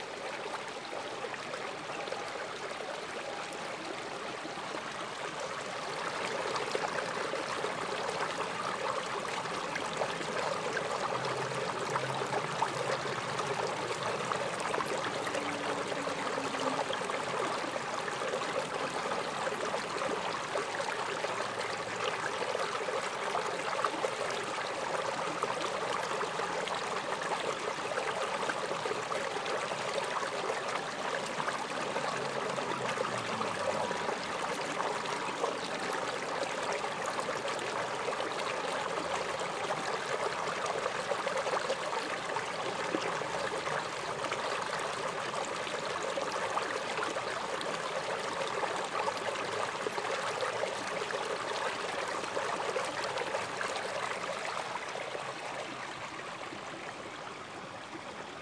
Water